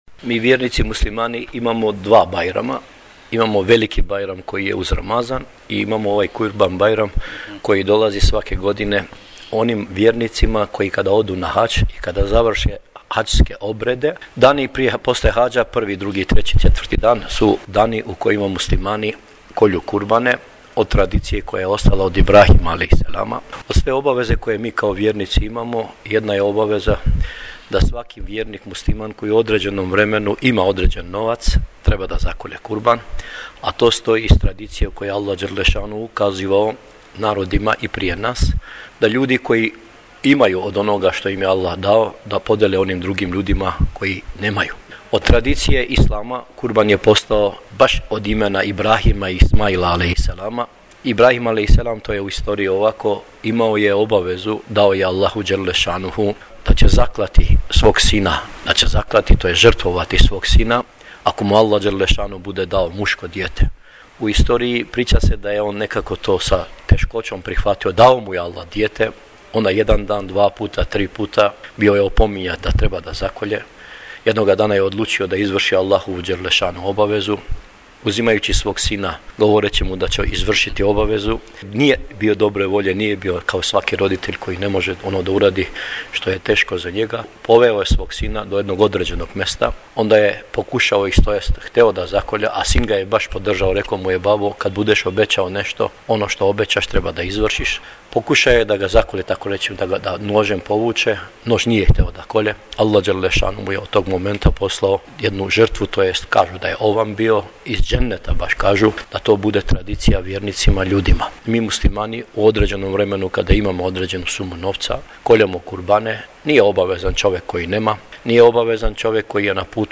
Poslije sabah namaza, u džamijama vjernici obavljaju bajram namaz koji sa posebnom radošću vode imami.